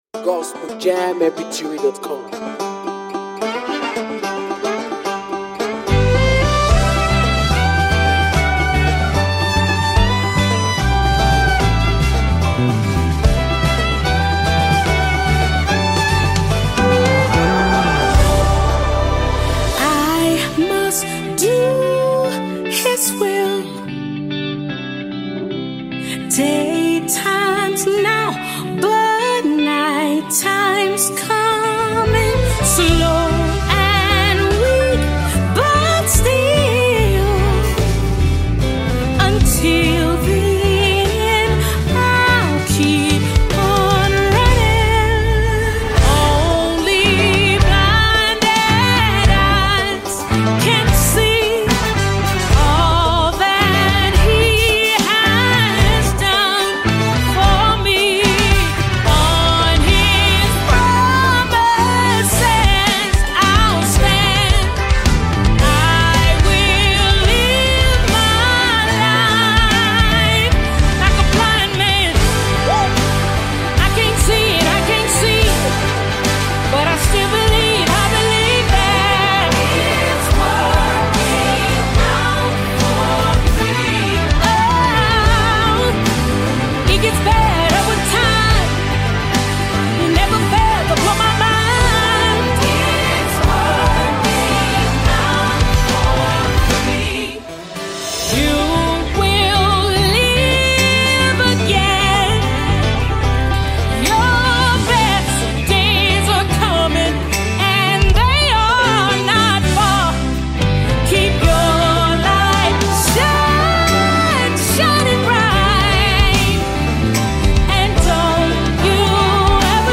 melodious sound